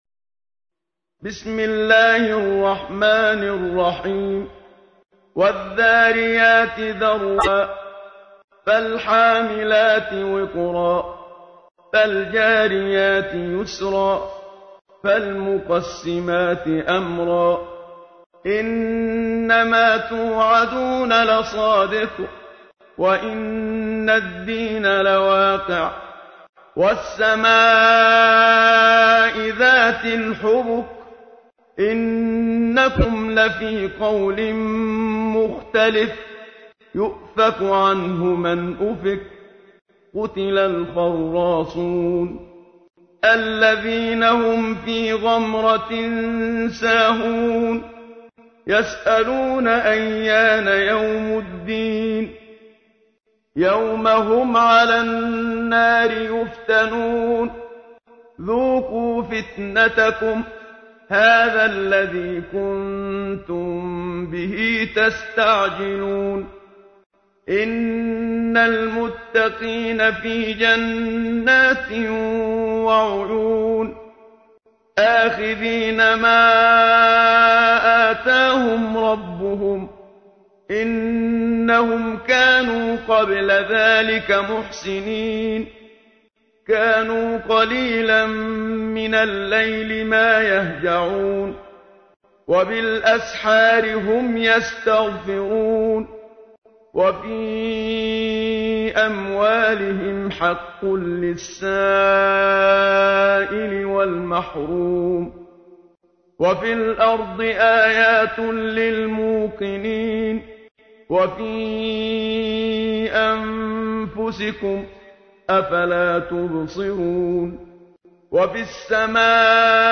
تحميل : 51. سورة الذاريات / القارئ محمد صديق المنشاوي / القرآن الكريم / موقع يا حسين